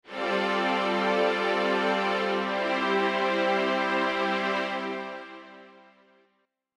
Disonancia-resuelta1.mp3